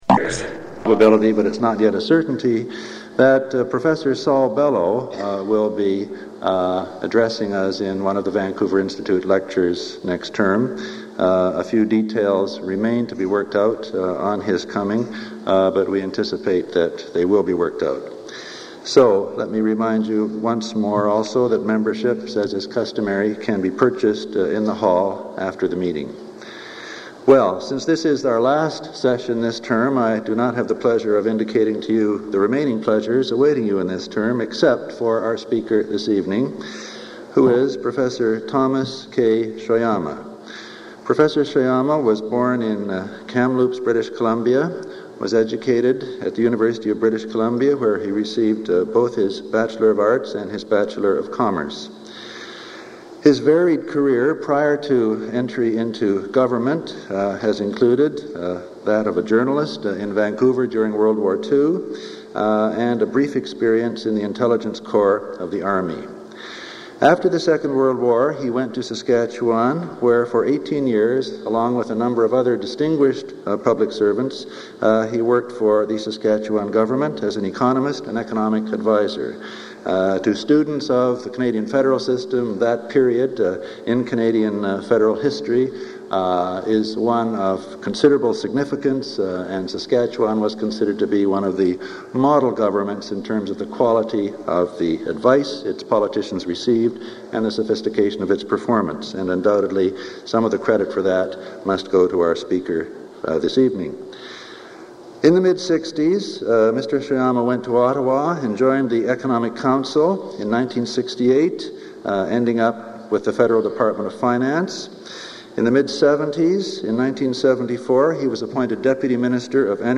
Item consists of a digitized copy of an audio recording of a Vancouver Institute lecture given by Thomas Shoyama on November 28, 1981.